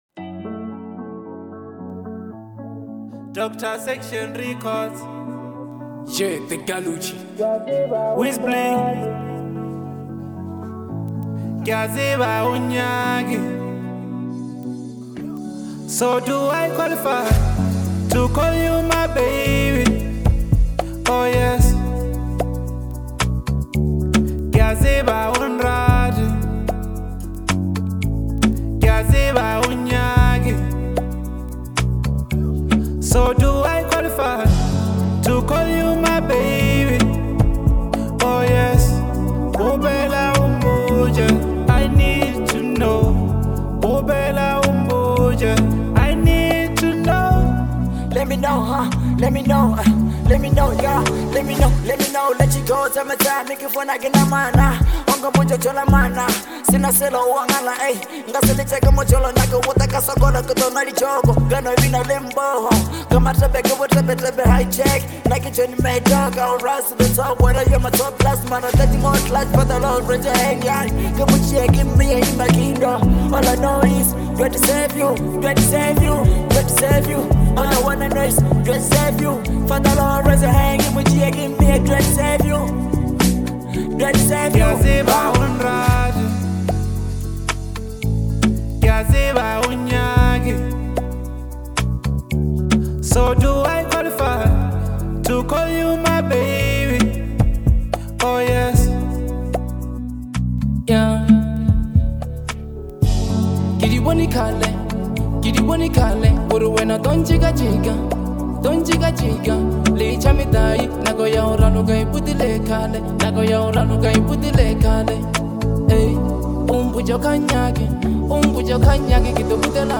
hipop
A love song